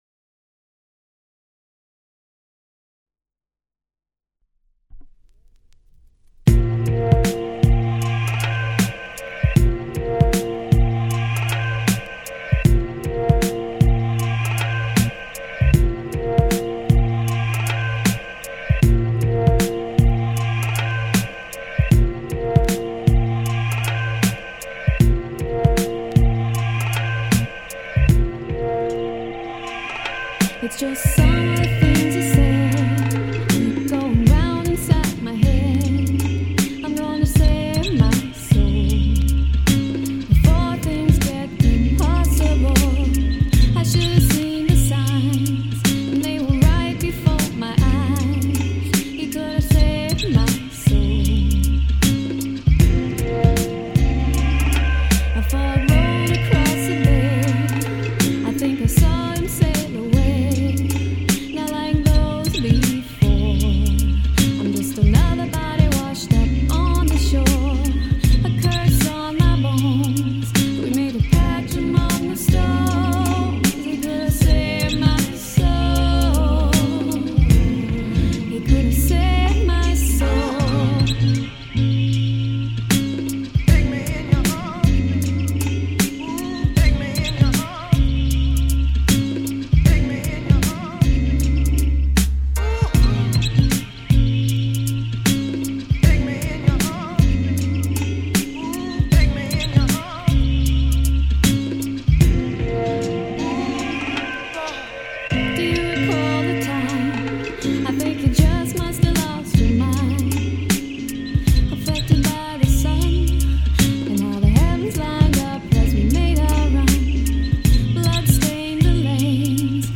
dance/electronic
Funk